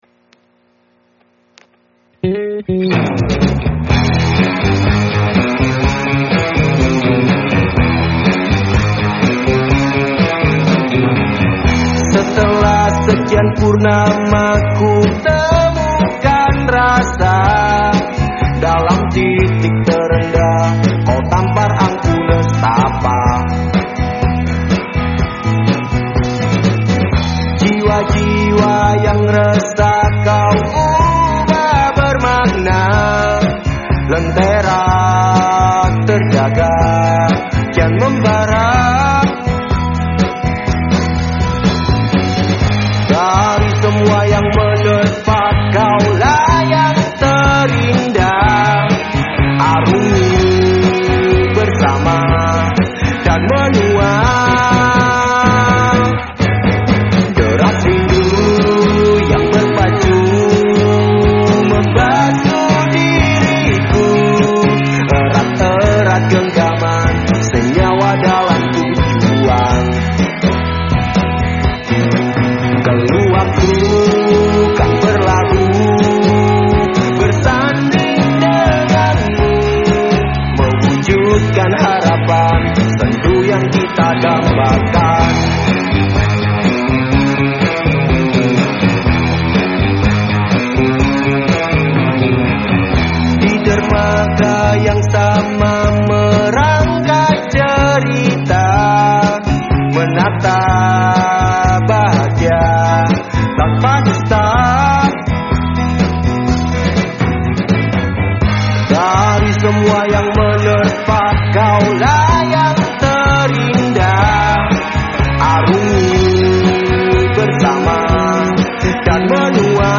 Surabaya Pop
retro pop